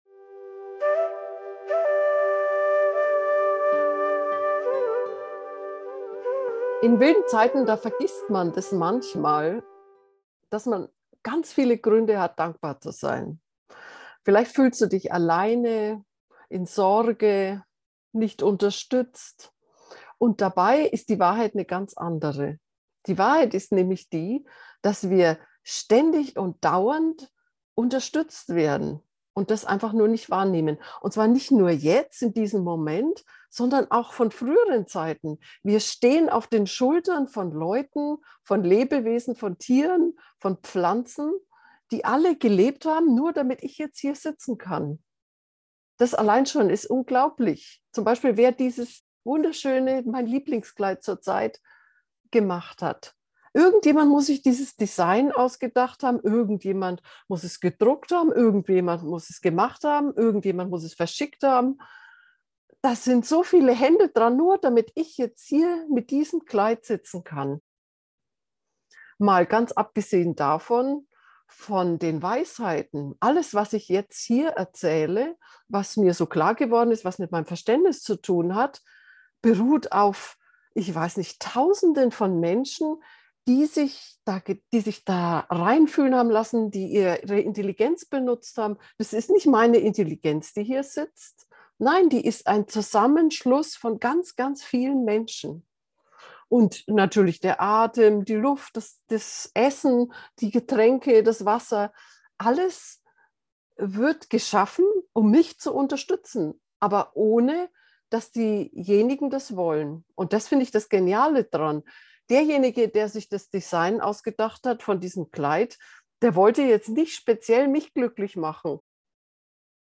In dieser geführten Meditation wirst du dann auch vielleicht erkennen, dass du nicht nur ein Teil dieses großen Zusammenspiels bist.
dankbarkeit-gefuehrte-meditation